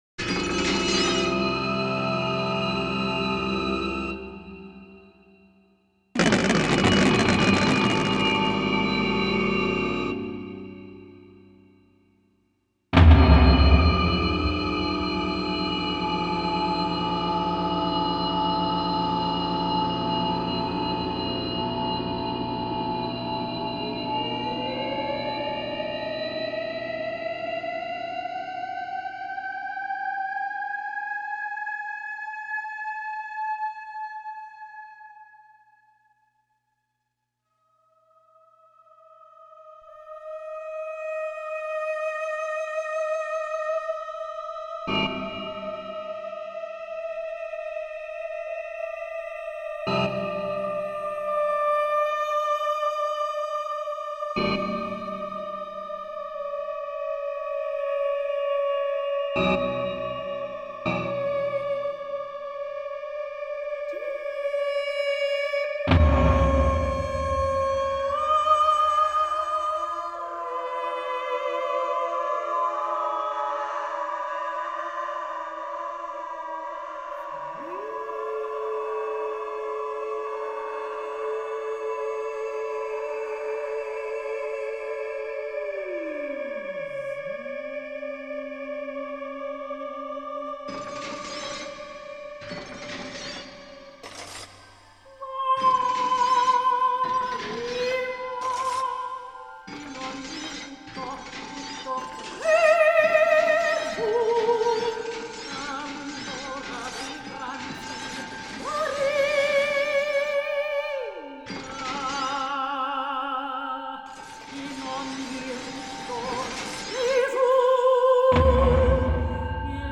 soprano Texts Score